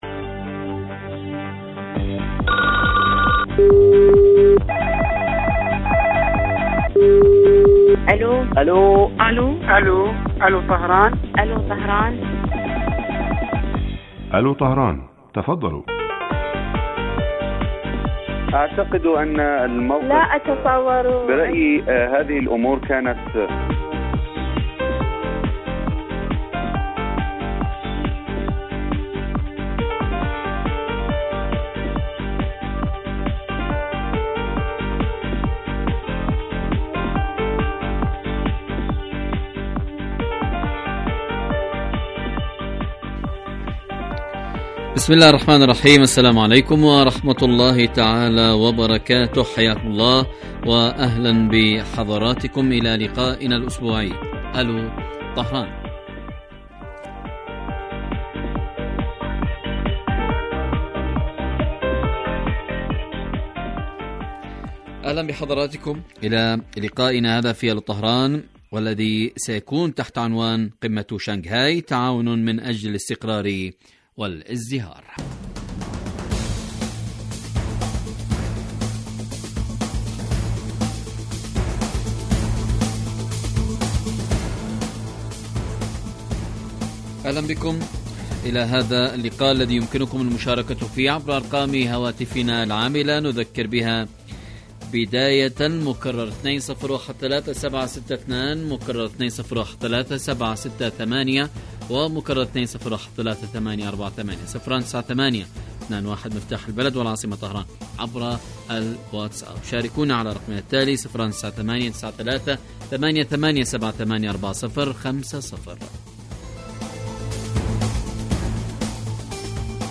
برنامج حي يهدف إلى تنمية الوعي السياسي من خلال طرح إحدى قضايا الساعة الإيرانية والعالمية حيث يتولى مقدم البرنامج دور خبير البرنامج أيضا ويستهل البرنامج بمقدمة يطرح من خلال محور الموضوع على المستمعين لمناقشته عبر مداخلاتهم الهاتفية
يبث هذا البرنامج على الهواء مباشرة مساء أيام الجمعة وعلى مدى ثلاثين دقيقة